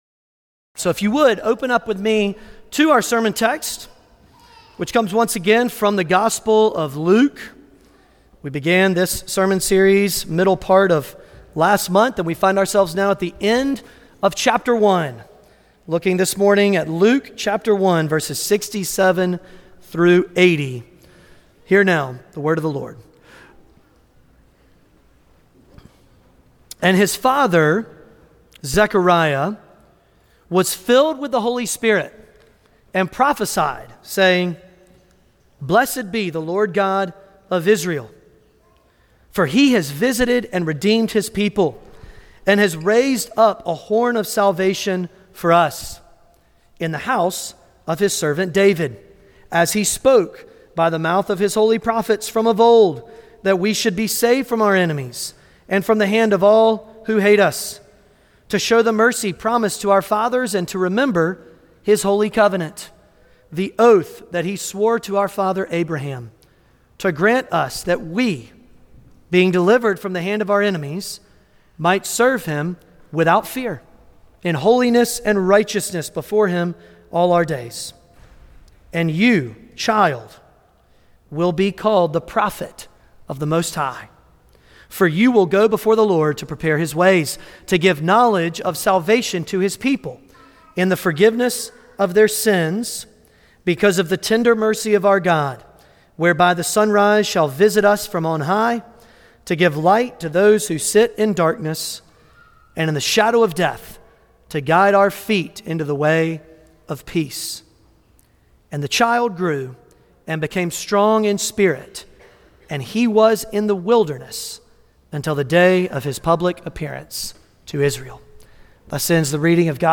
Passage: Luke 1:67-80 Service Type: Sunday Morning Luke 1:67-80 « Joy And Fear The City Of David